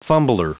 Prononciation du mot fumbler en anglais (fichier audio)
Prononciation du mot : fumbler